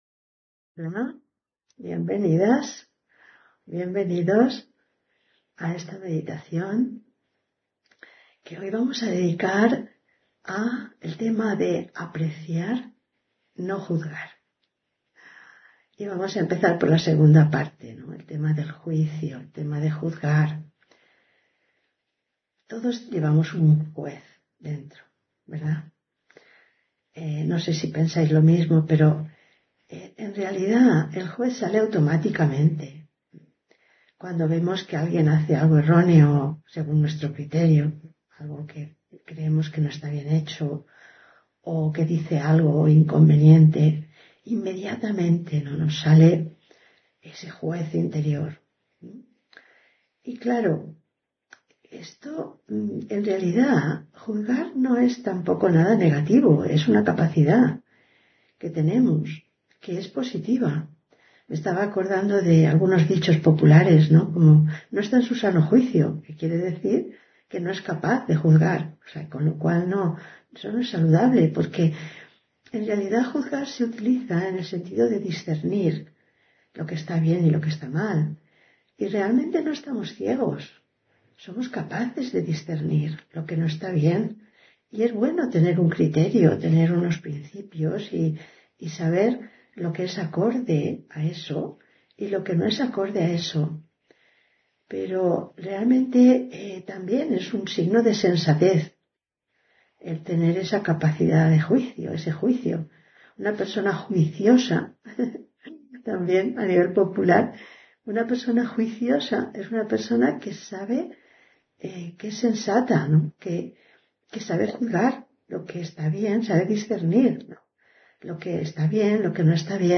Meditación Raja Yoga y Charla: Meditar para sentir fortaleza (28 Abril 2021) On-line desd ...